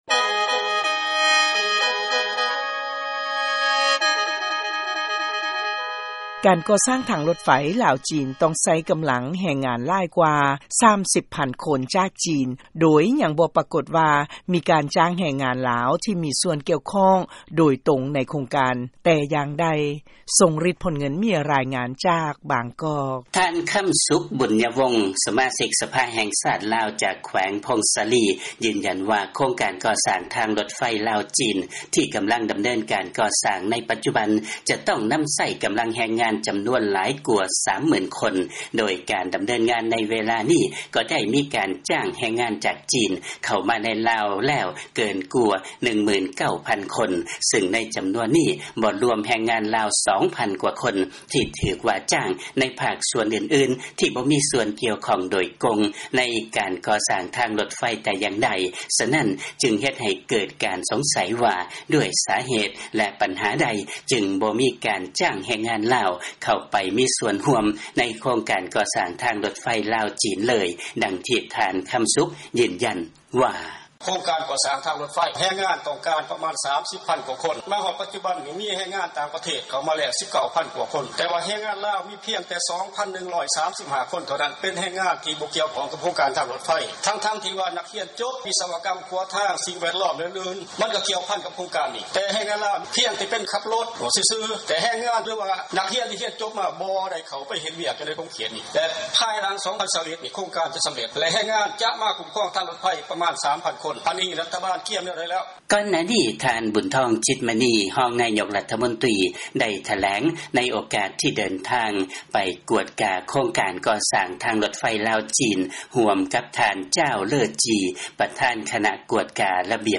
ເຊີນຟັງລາຍງານເລື້ອງທາງລົດໄຟຄວາມໄວສູງ ລາວ-ຈີນ